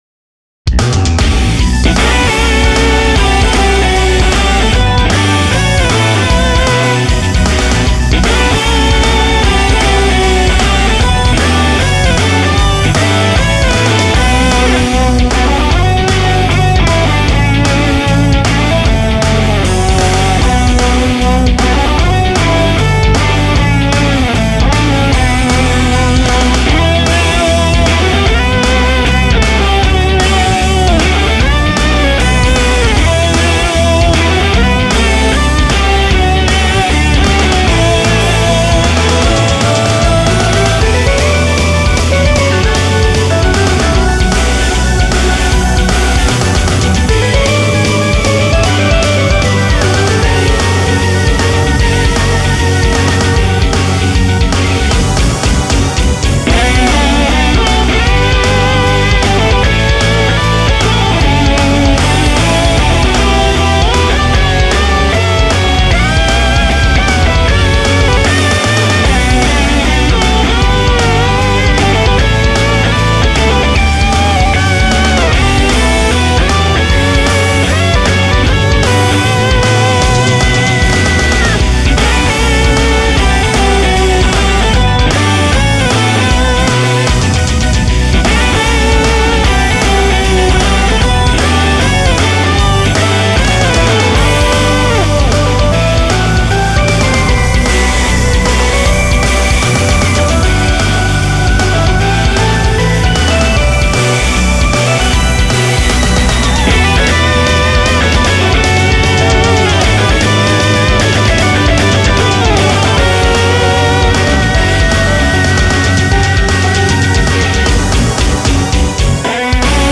音も良くなったしノリも良くなったよ！